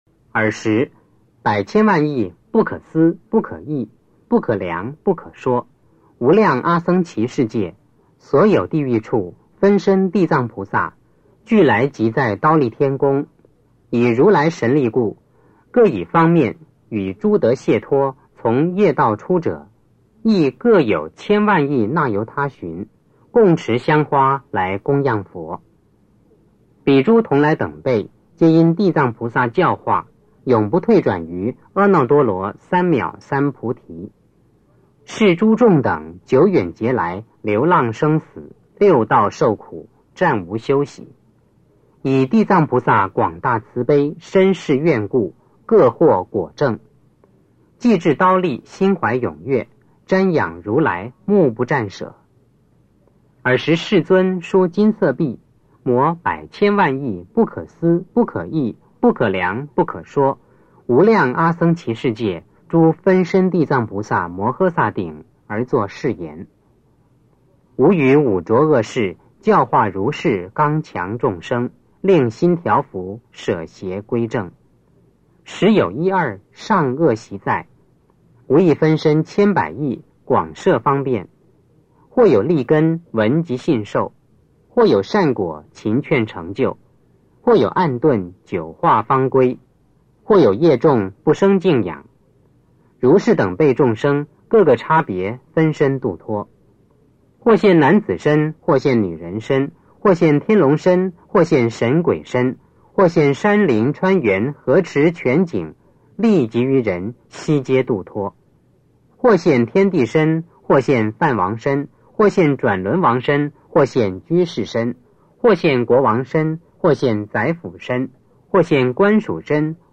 地藏经(男声念诵）2-3
地藏经(男声念诵）2-3 诵经 地藏经(男声念诵）2-3--未知 点我： 标签: 佛音 诵经 佛教音乐 返回列表 上一篇： 普贤菩萨行愿品 下一篇： 地藏经(男声念诵）5 相关文章 极乐歌--群星 极乐歌--群星...